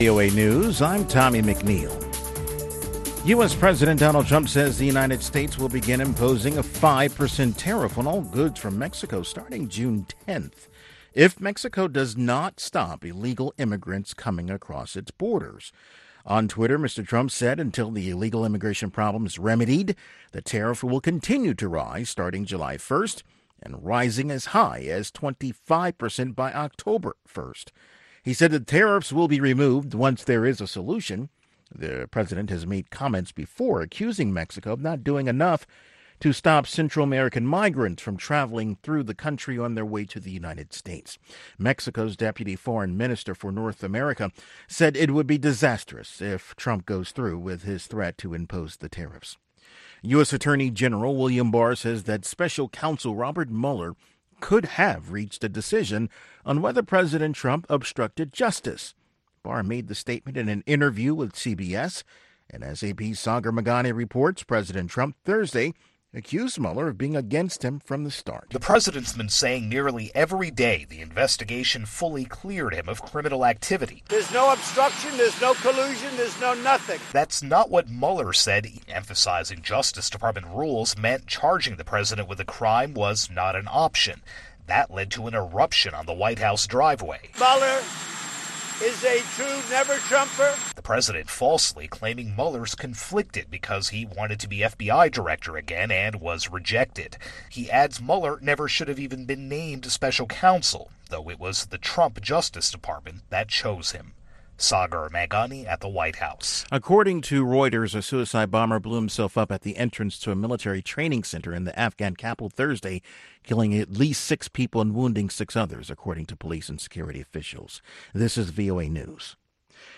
African Beat showcases the latest and the greatest of contemporary African music and conversation. From Benga to Juju, Hip Life to Bongo Flava, Bubu to Soukous and more